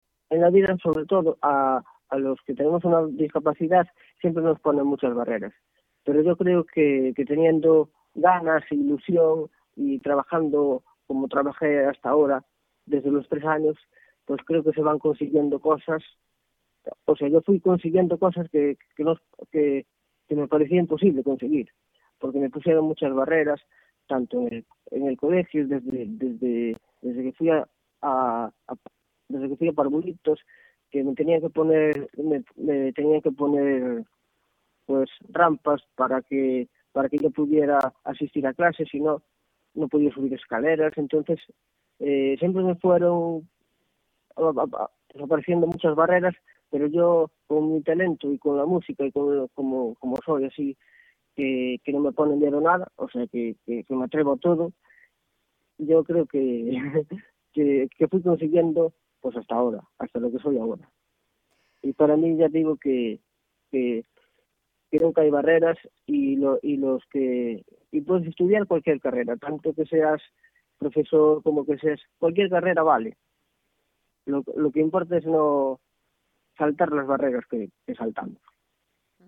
Así que el sueño se materializa: “mi pasión es la música y voy a luchar por conseguirlo. Luchando, luchando... aquí estoy ahora formato MP3 audio(0,18 MB), comenta risueño, al otro lado del hilo telefónico, dedicándonos un alto en el camino de su gira para compartir con nosotros emociones, sentimientos a flor de piel y el deseo, que atisba cada vez más cercano, de alcanzar la gloria del artista con mayúsculas.